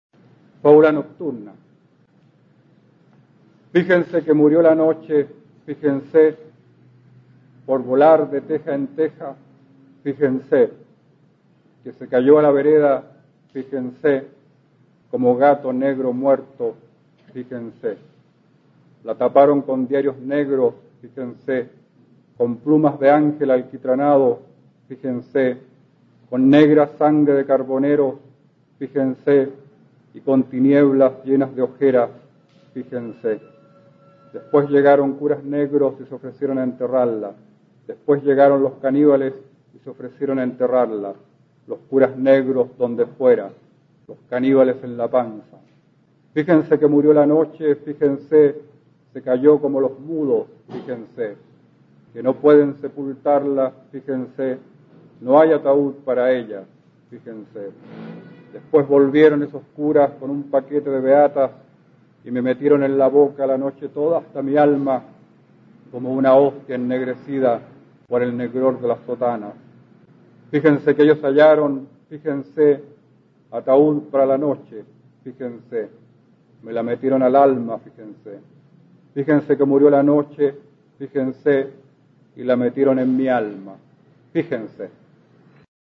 A continuación se puede escuchar al poeta chileno Óscar Hahn, perteneciente a la Generación del 60, recitando su poema Fábula nocturna, compuesto cuando el autor tenía apenas 17 años y publicado en el libro "Esta rosa negra" (1961).
Poema